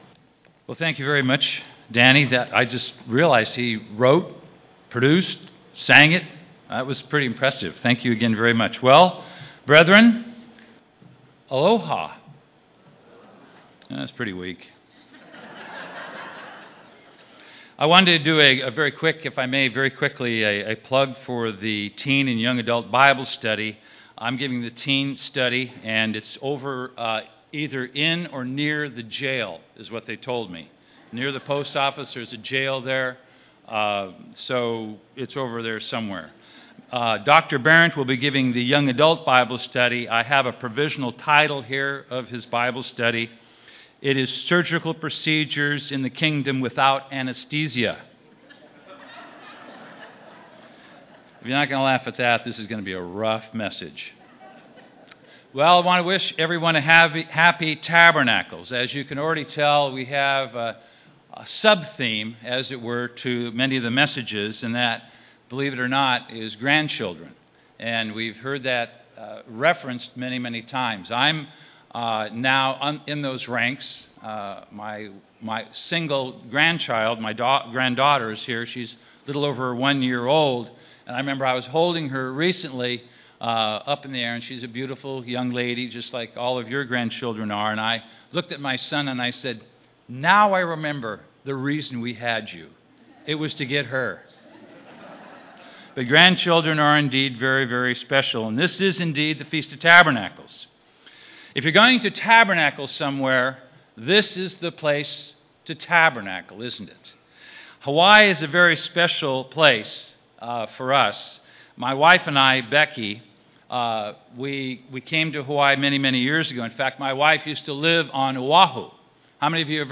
This sermon was given at the Maui, Hawaii 2013 Feast site.